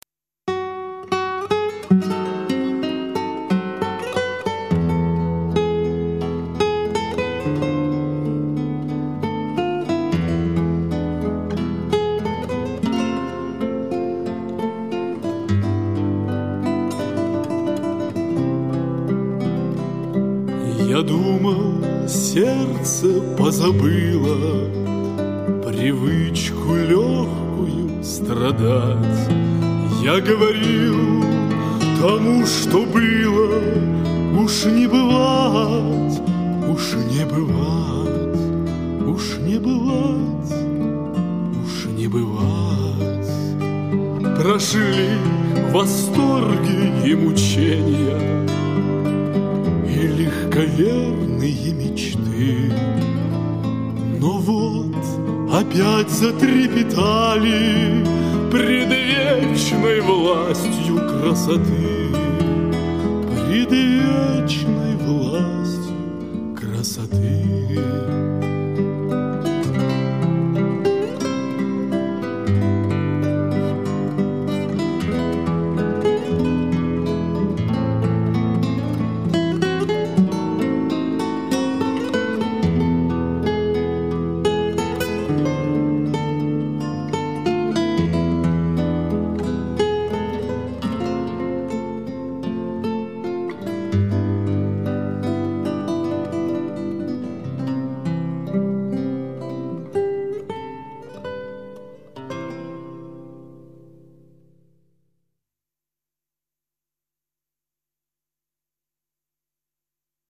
Вы услышите старинные русские романсы, а также романсы, написанные самим исполнителем на стихи поэтов золотого и серебряного века.
гитара, вокал